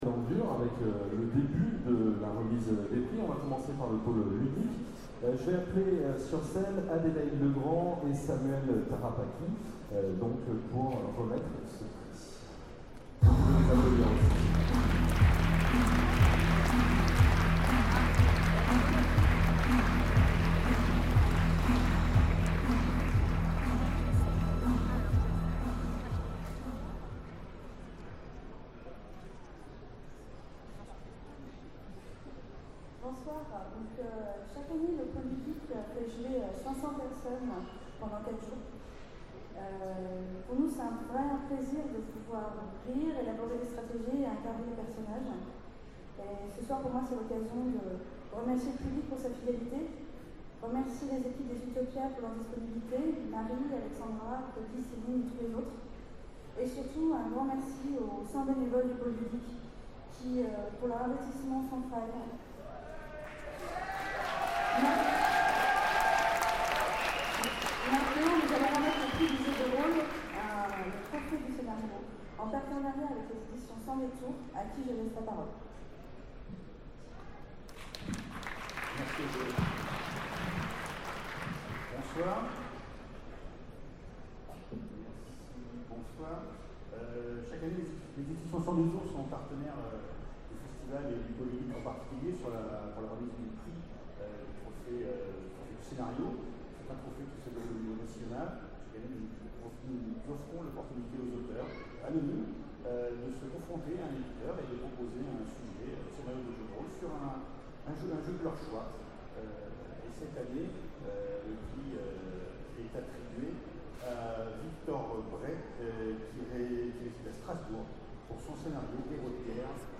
Utopiales 12 : Remise du prix Utopiales européen